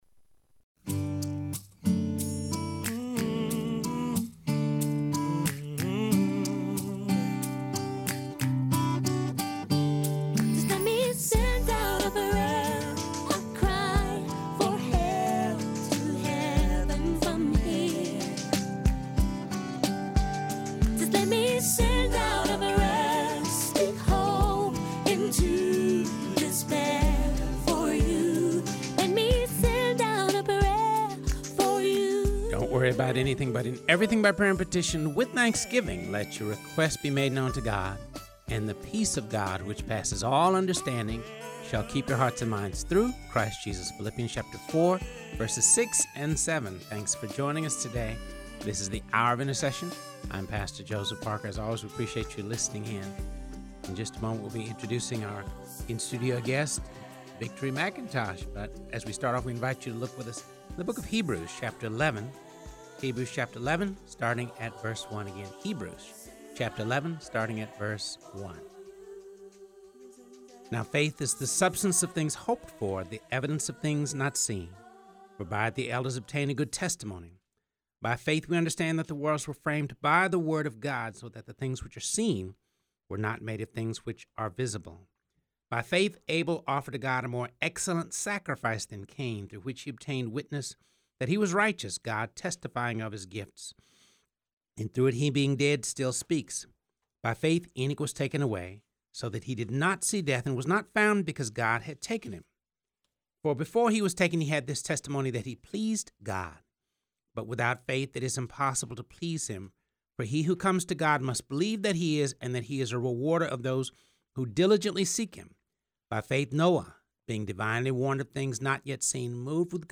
in studio to talk about the importance of spiritual discipline.